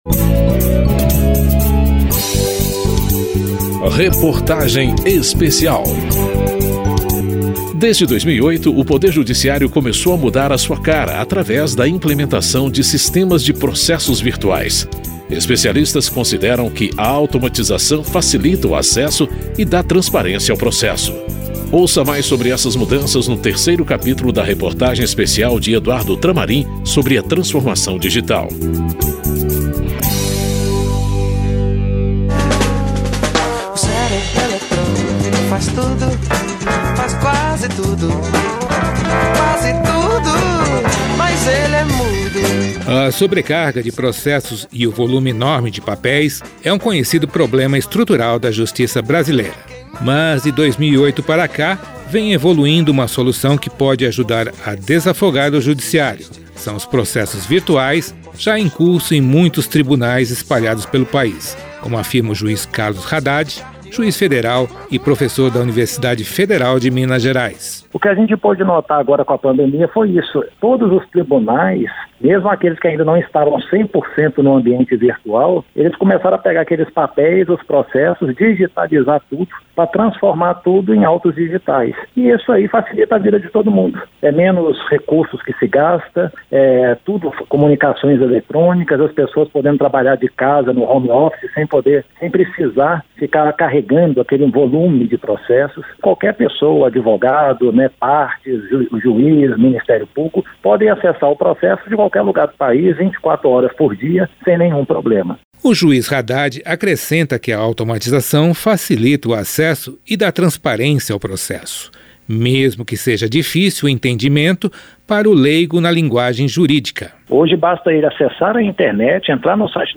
Reportagem Especial
Ouvidos nesse capítulo: Carlos Haddad, juiz federal e professor da Universidade Federal de Minas Gerais